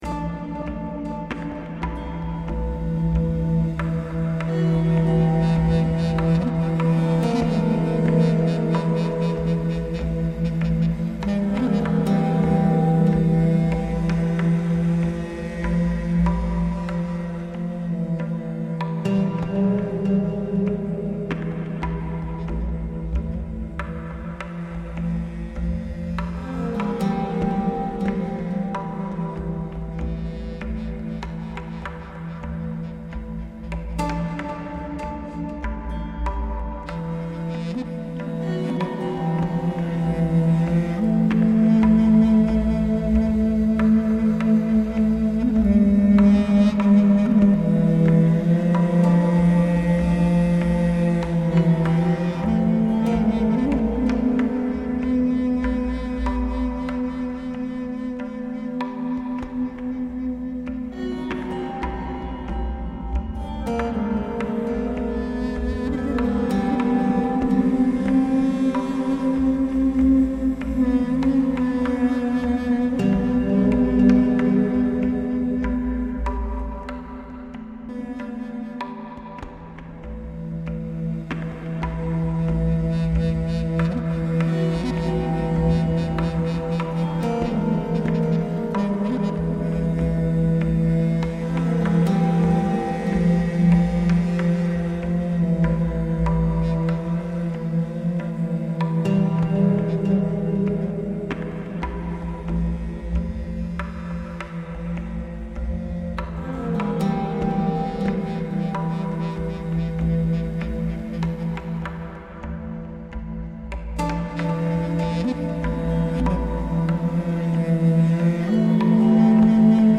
Scary texture for Uday Hussein.